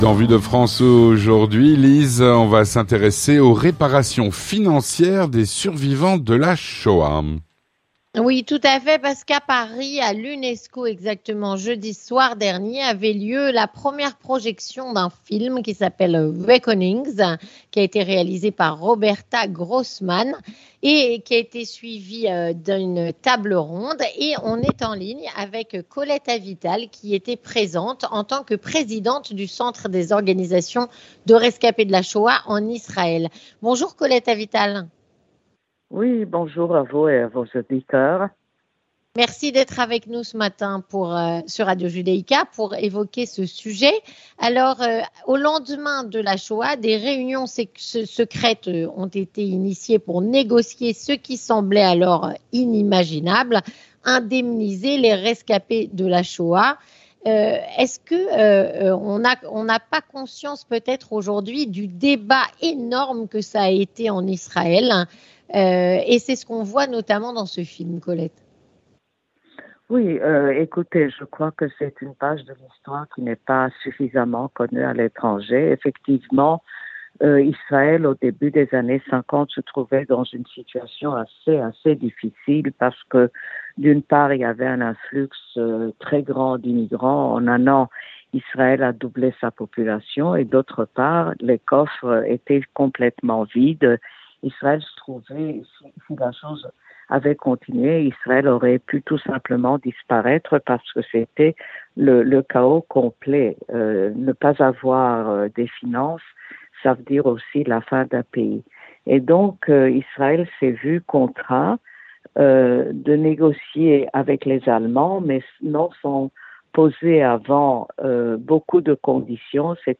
Avec Colette Avital, présidente du Centre des organisations de survivants de l'Holocauste en Israël